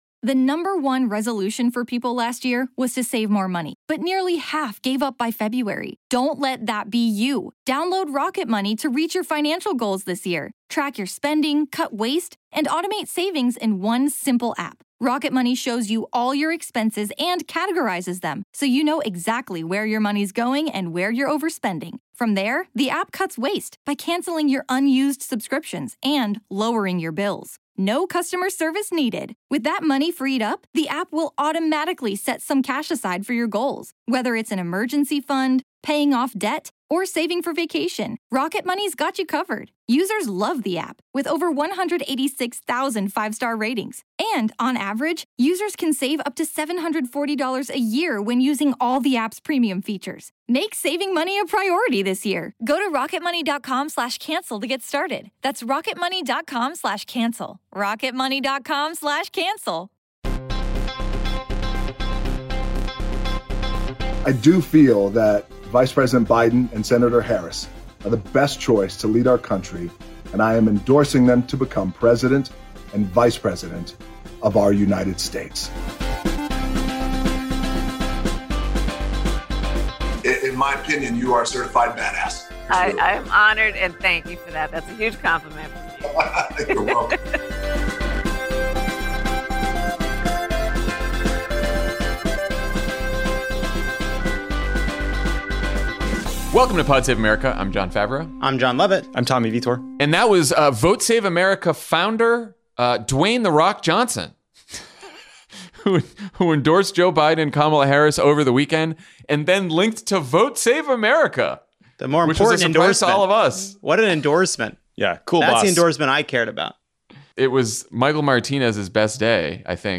Trump’s taxes reveal that he’s heavily in debt and doesn't pay income taxes, Republicans want to make Amy Coney Barrett’s nomination about her Catholicism instead of her opposition to the Affordable Care Act, and Joe Biden prepares to face off with Donald Trump in the first of three presidential debates. Then Obama campaign manager David Plouffe talks to Jon Favreau about prepping for debates and the battleground states that Joe Biden needs to hit 270 electoral votes.